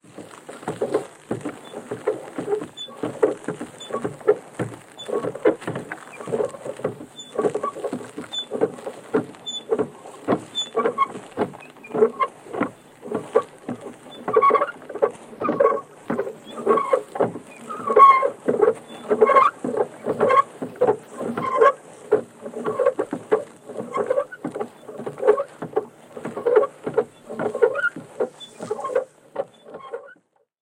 Скрип колеса кареты